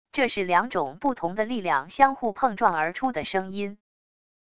这是两种不同的力量相互碰撞而出的声音wav音频